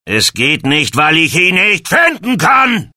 "Excellent synchronisation"